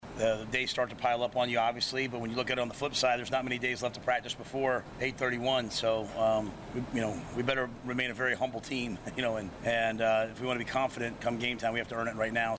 After Tuesday’s practice, Matt Rhule addressed the challenges associated with fall camp and the future of the younger players on the team.